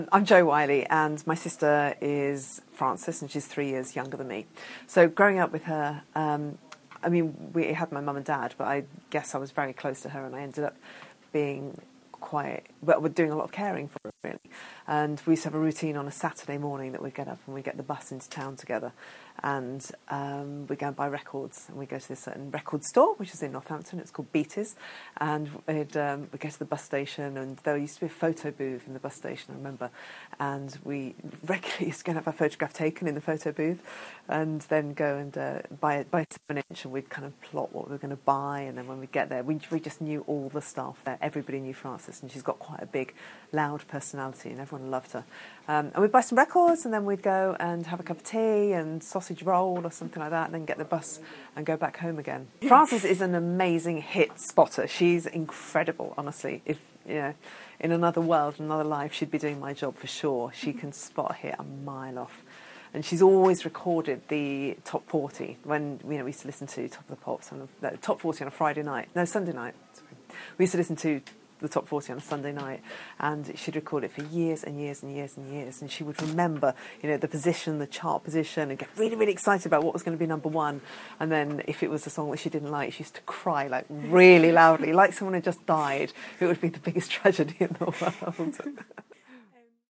Radio 2 DJ Jo Whiley speaks to us